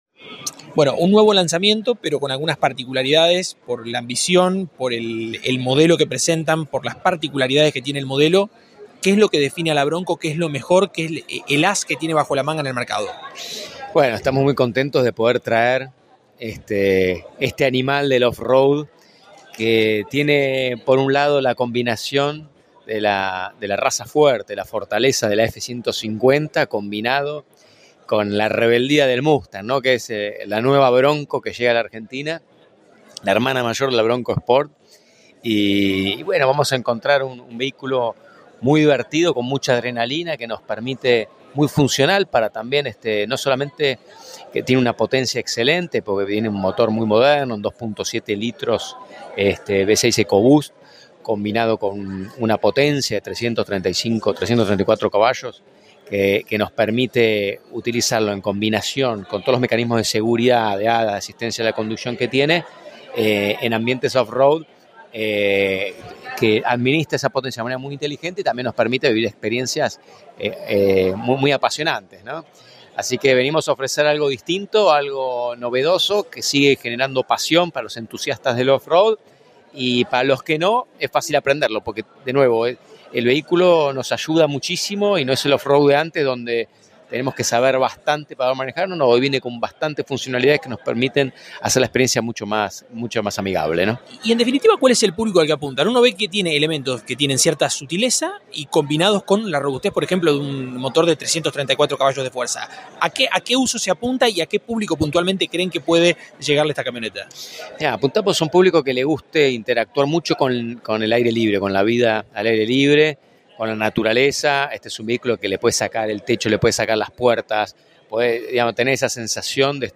En el evento de lanzamiento oficial de la Bronco